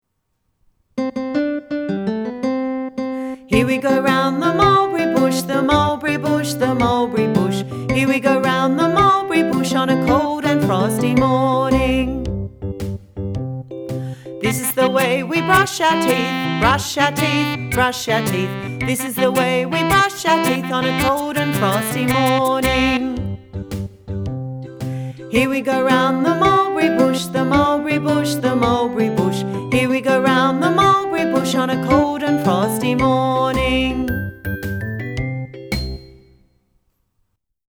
Here We Go Round the Mulberry Bush is in six eight time encouraging a skipping, light-footed response.
Here-We-Go-Round-the-Mulberry-Bush-voc.mp3